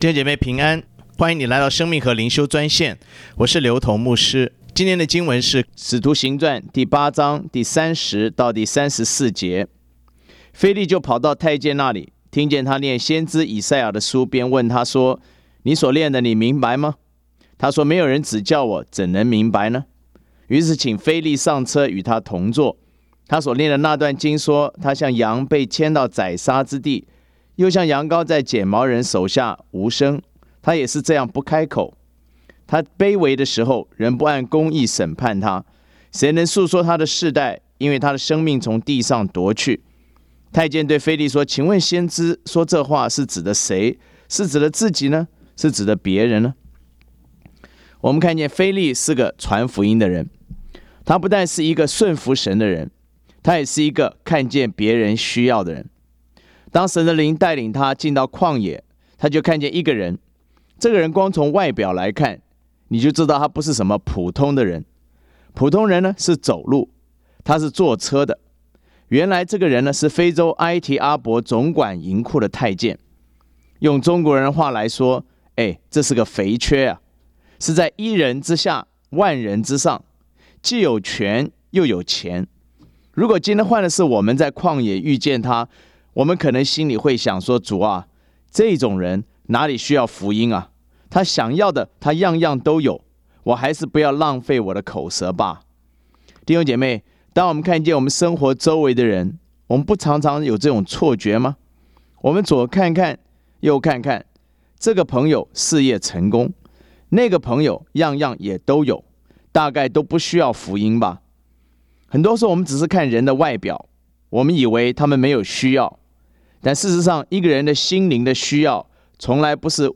选读经文:
藉着每天五分钟电话分享，以生活化的口吻带领信徒逐章逐节读经。